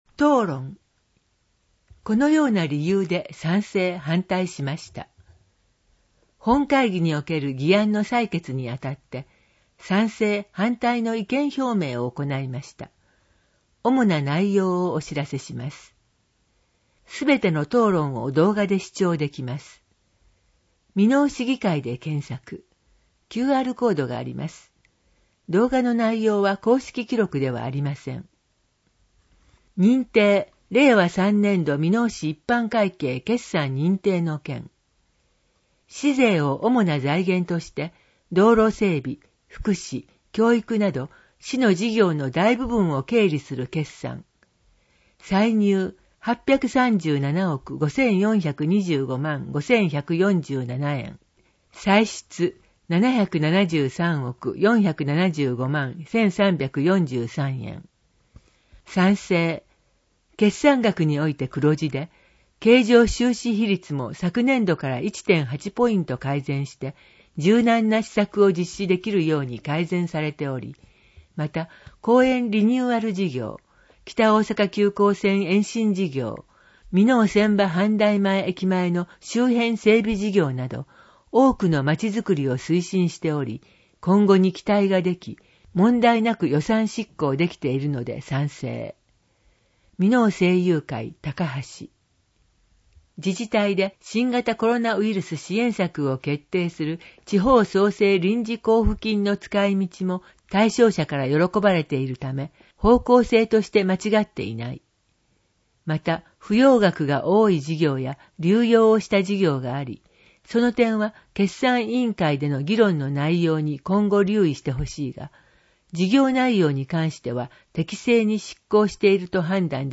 みのお市議会だより「ささゆり」の内容を声で読み上げたものを掲載しています。